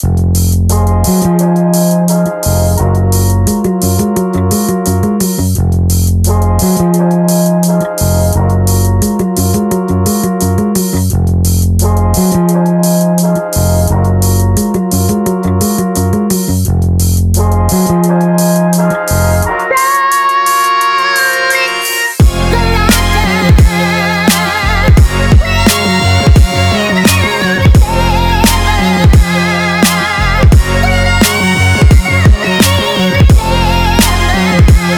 2025-06-06 Жанр: Танцевальные Длительность